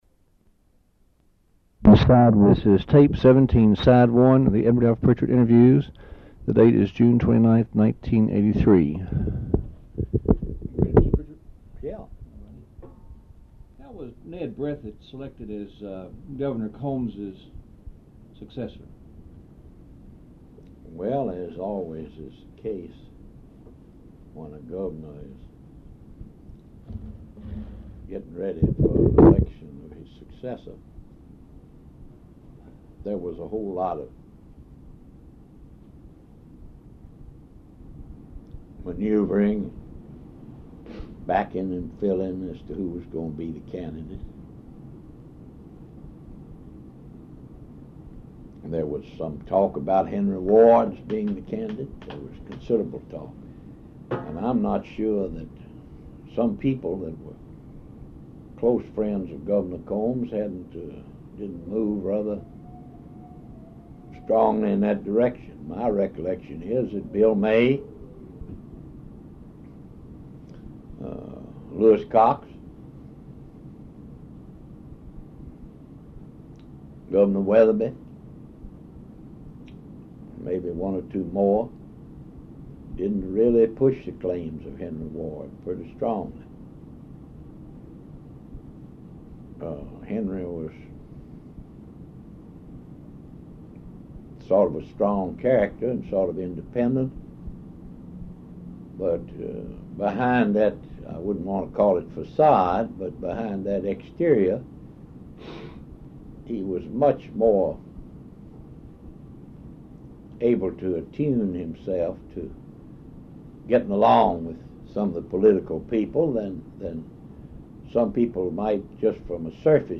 Oral History Interview with Edward F. Prichard, Jr., June 29, 1983 Part 1